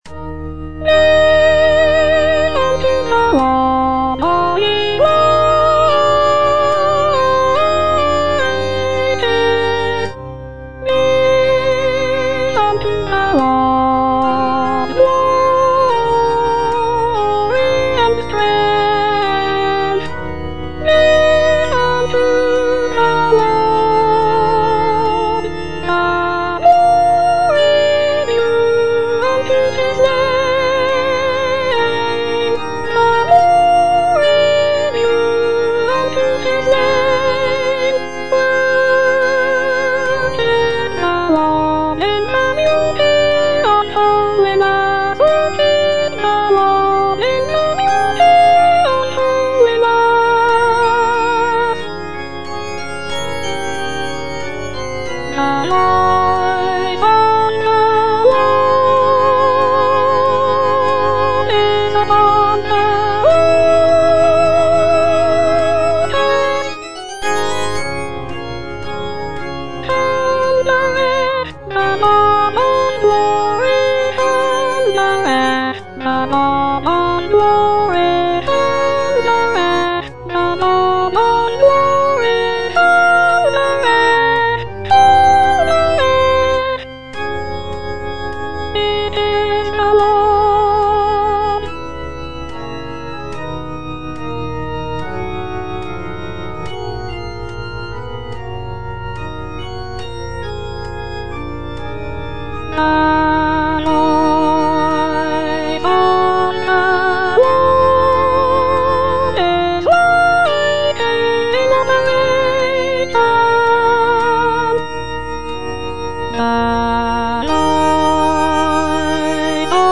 E. ELGAR - GIVE UNTO THE LORD Soprano II (Voice with metronome) Ads stop: auto-stop
"Give unto the Lord" is a sacred choral work composed by Edward Elgar in 1914.